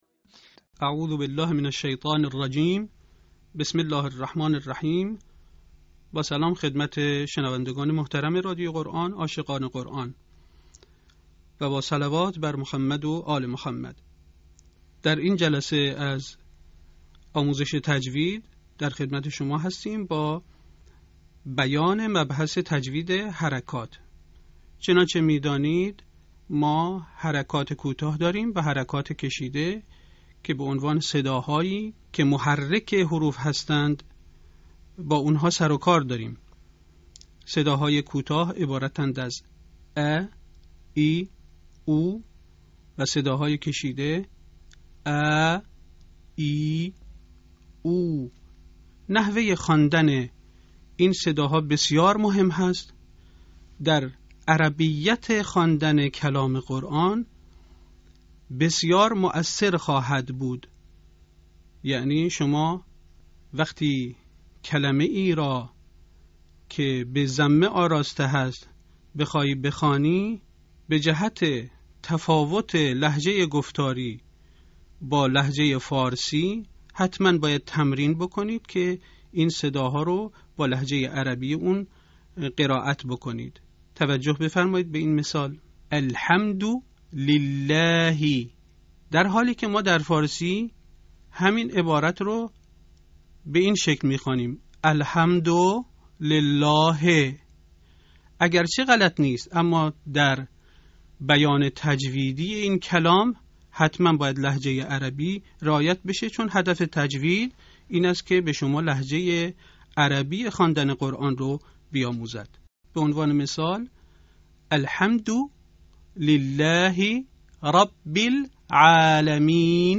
به همین منظور مجموعه آموزشی شنیداری (صوتی) قرآنی را گردآوری و برای علاقه‌مندان بازنشر می‌کند.
آموزش تجوید